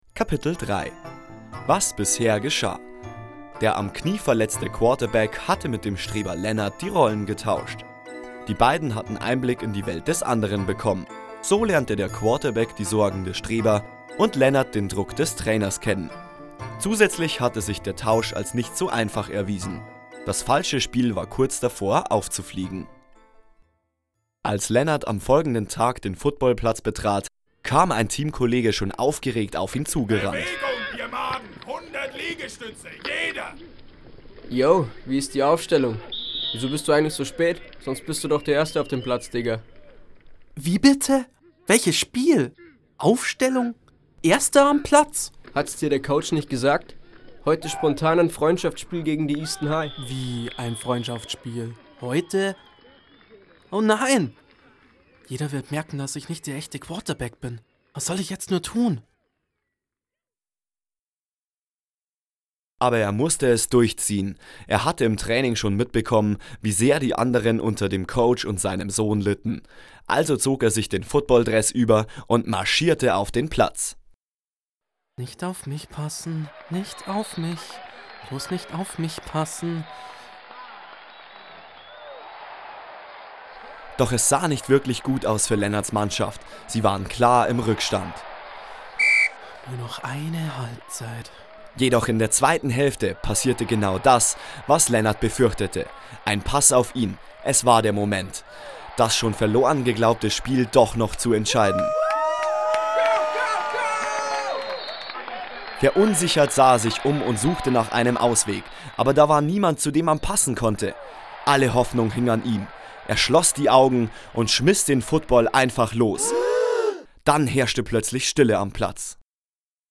Hoerspiel_NichtNochEinTeeniHoerspiel_Teil3.mp3